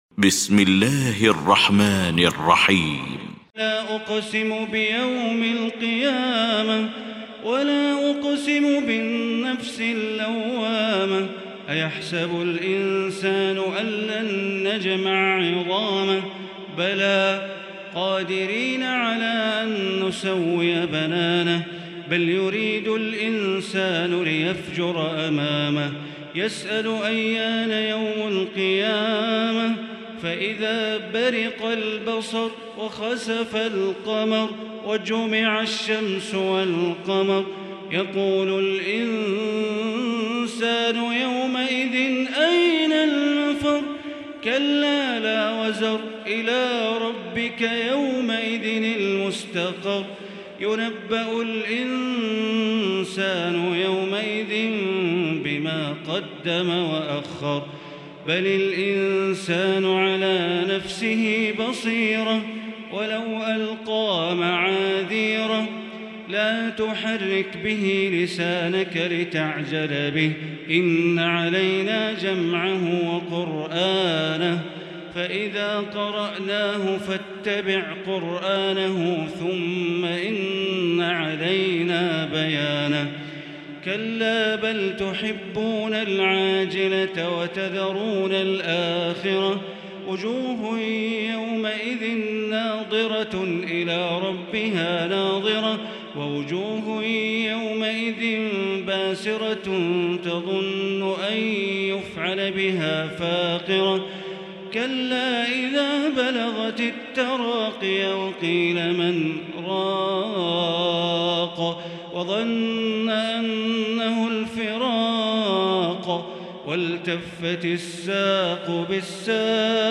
المكان: المسجد الحرام الشيخ: معالي الشيخ أ.د. بندر بليلة معالي الشيخ أ.د. بندر بليلة القيامة